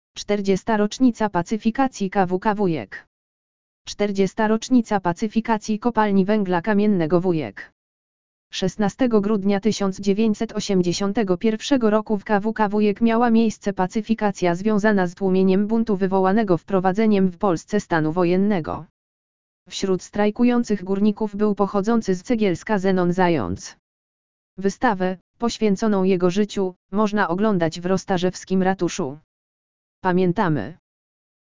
audio_lektor_40_rocznica_pacyfikacji_kwk_wujek.mp3